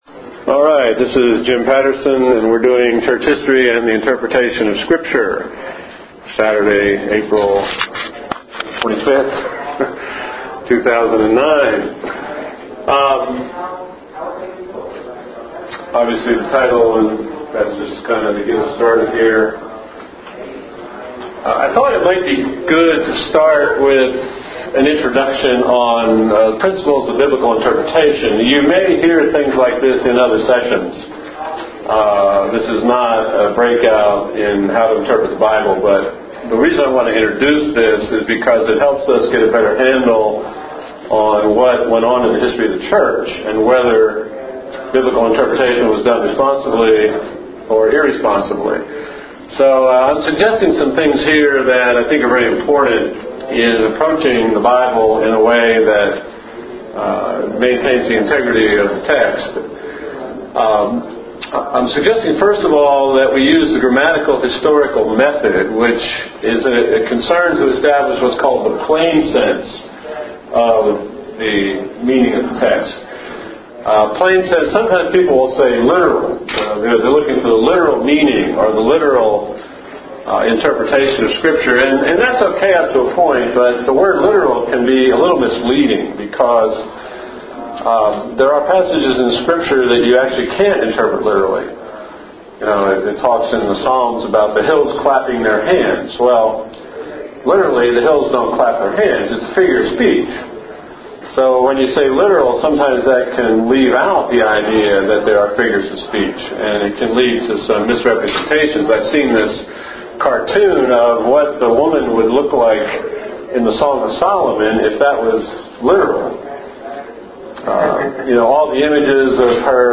Breakout Session